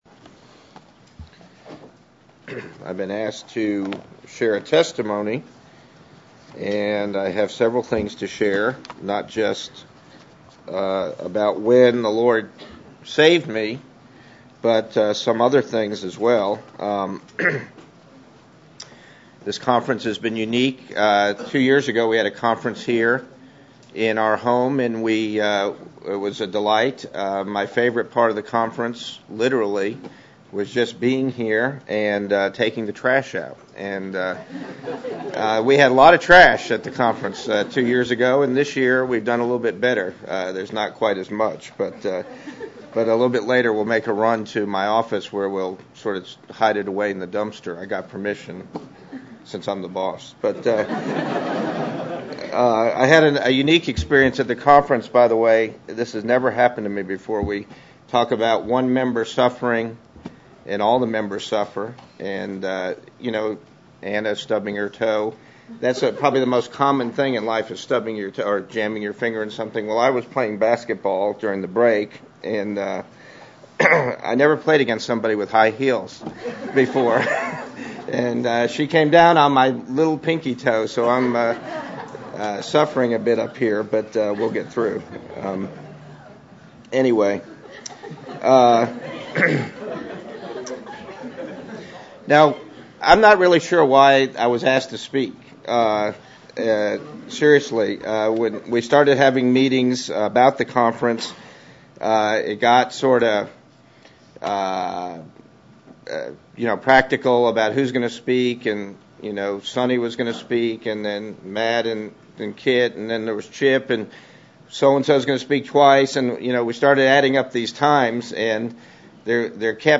2009 Memphis Conference: A Call To Build